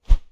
Close Combat Swing Sound 19.wav